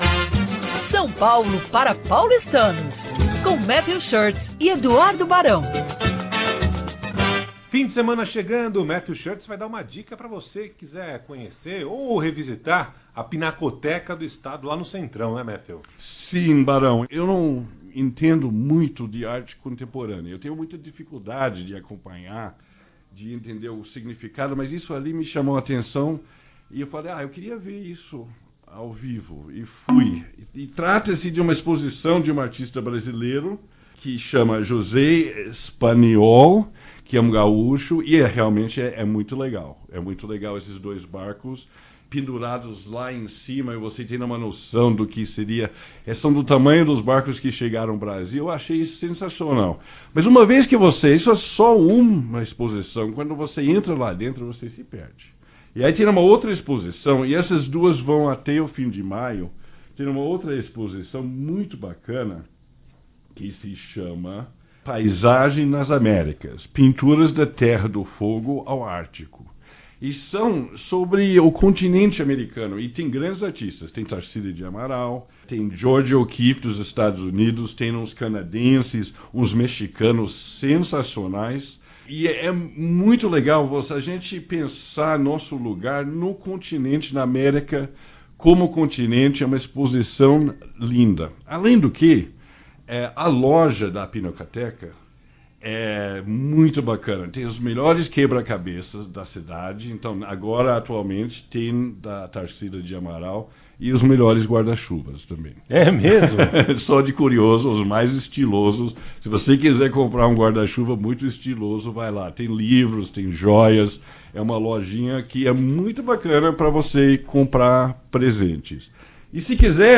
Radio announcement: “São Paulo para Paulistanos: Exposições TIAMM SCHUOOMM CASH! e Paisagem nas Américas estão em cartaz na Pinacoteca do Estado,” Rádio Bandnews FM 96.9, São Paulo, April 8, 2016